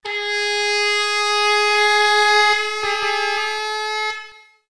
air_horns_1.wav